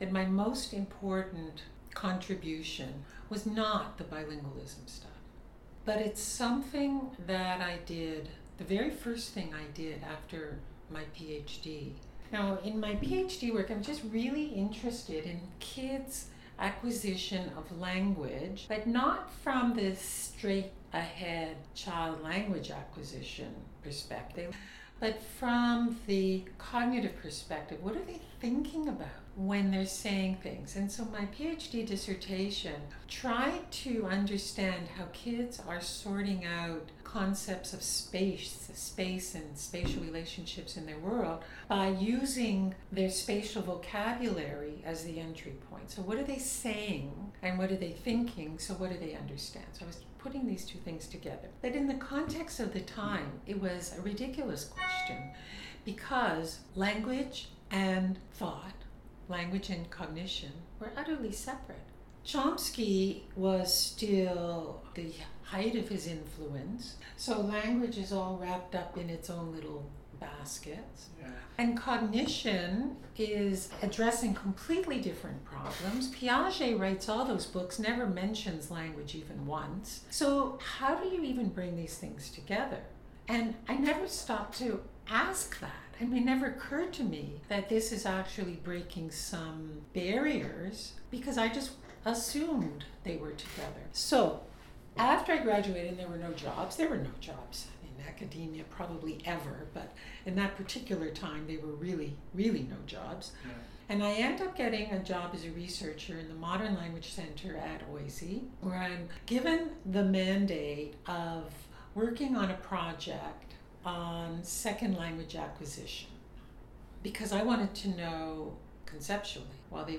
In meeting with me she prefaced the conversation (and the recording I took) with the following consideration: her vocal tract was degraded by a health issue, and is acoustically different than what she grew up with.